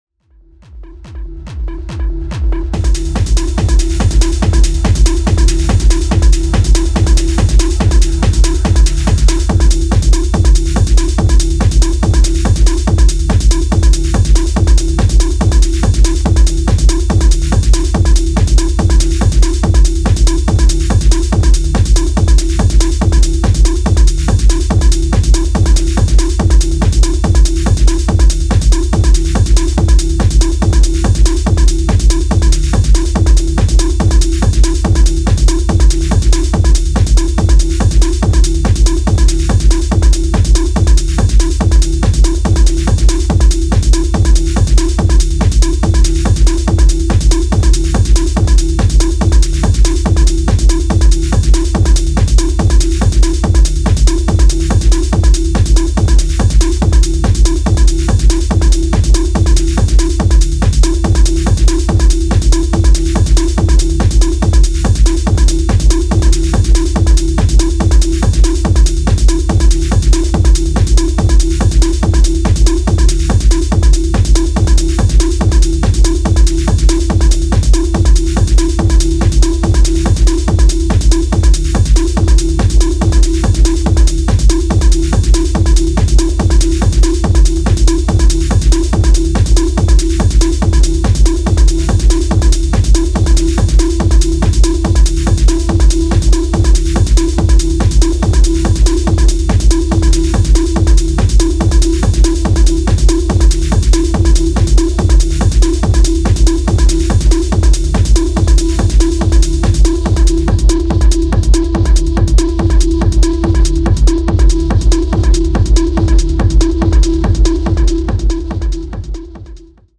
true raw and intense techno sound